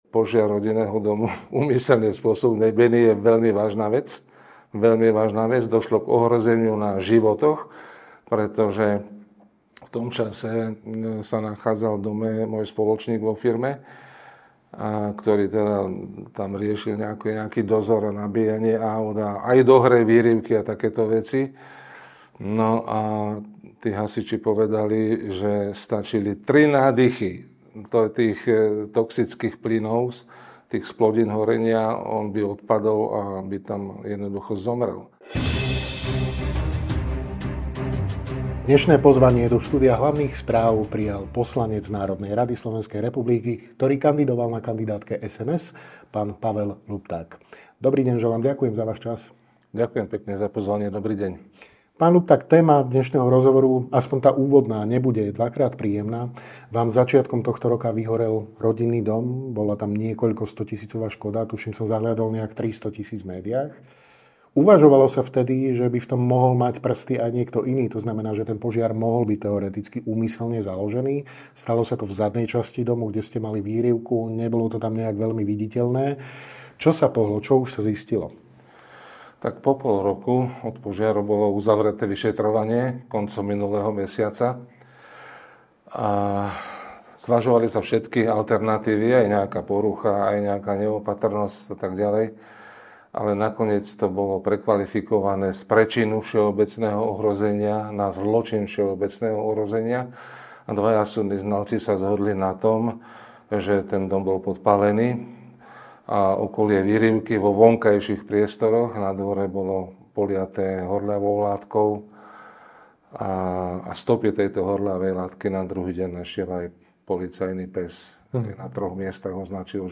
Rozprávali sme sa s poslancom NR SR a členom Strany vidieka, Ing. Pavlom Ľuptákom.